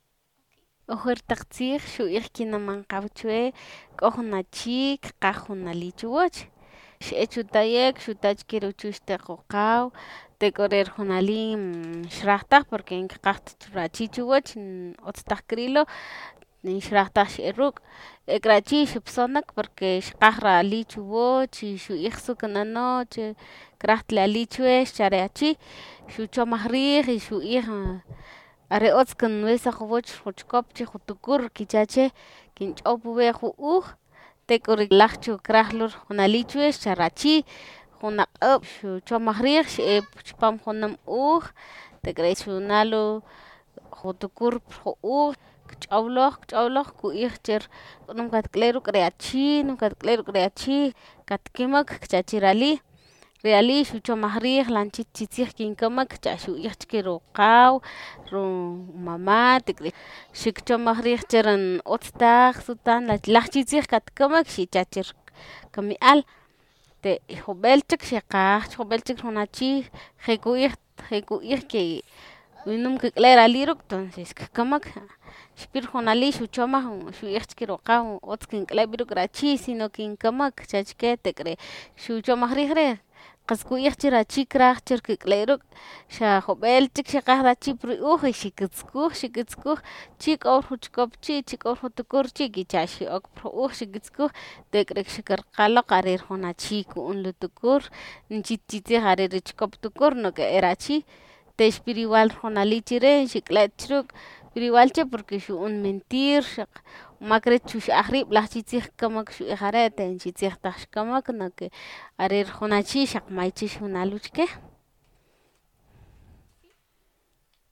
(Note that the audio files linked here have been edited to remove errors and repetitions, but the complete, original files, exactly as recorded, can be accessed through through the Archive of the Indigenous Languages of Latin America.)